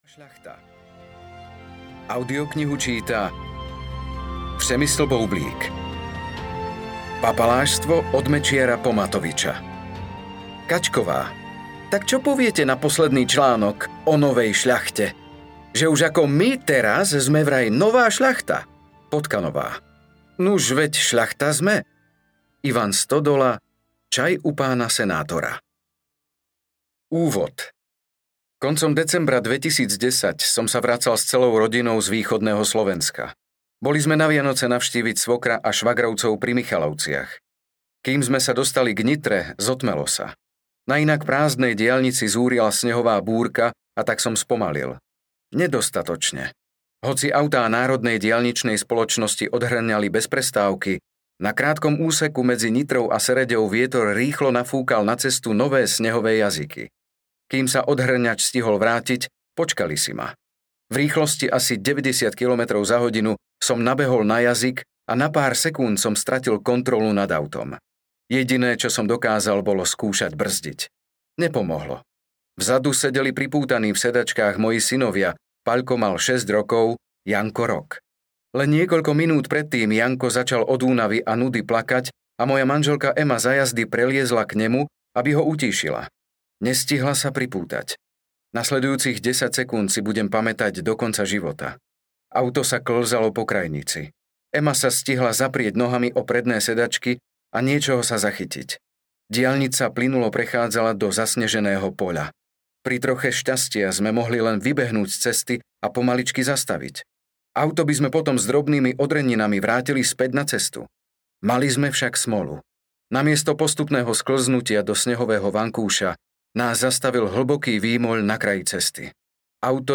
Nová šľachta audiokniha
Ukázka z knihy